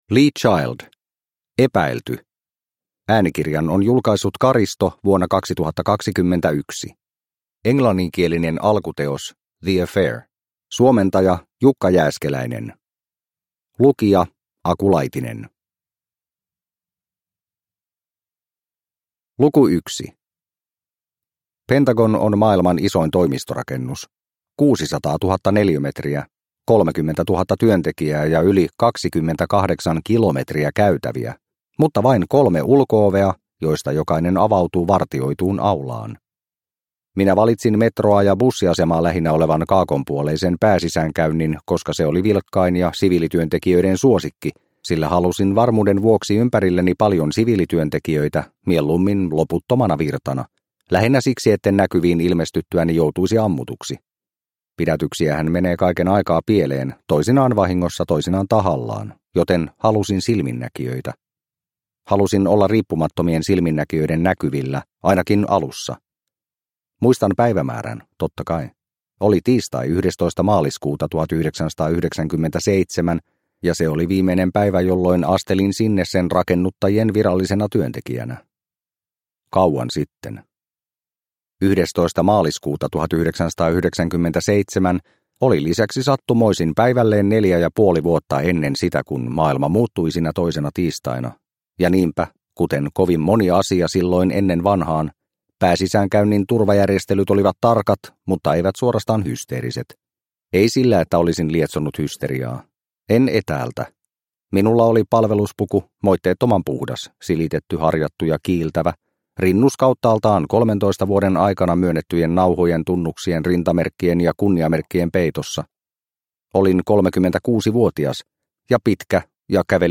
Epäilty – Ljudbok – Laddas ner